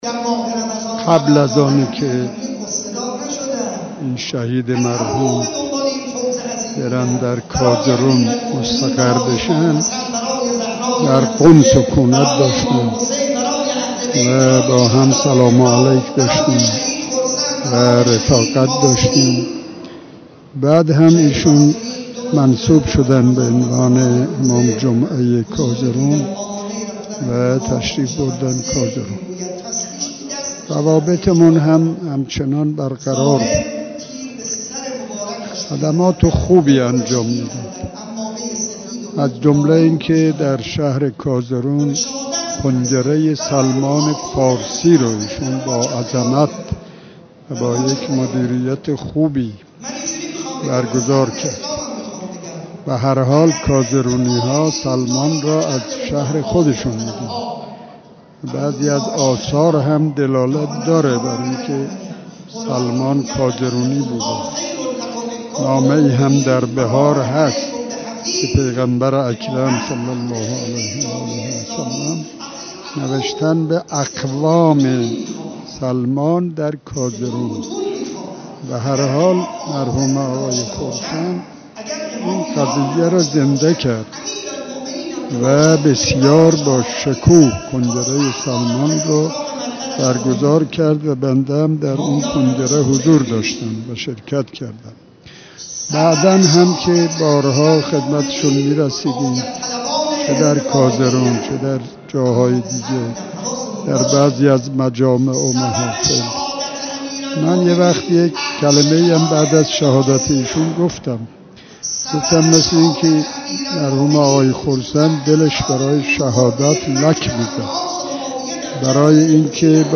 آیت‌الله احمد بهشتی نماینده مردم فارس در مجلس خبرگان رهبری در گفت وگو با خبرنگار خبرگزاری رسا، از روابط خوبش با شهيد حجت‌الاسلام والمسلمين خرسند خبر داد و گفت: حجت‌الاسلام خرسند در كازرون خدمات خوبي انجام داد؛ براي مثال با توجه به اينكه مردم كازرون سلمان فارسي را از شهر خود مي‌دانند حجت‌الاسلام والمسلمین خرسند نيز كنگره سلمان فارسي را با عظمت و مديريت خوبي در اين شهر برگزار كرد.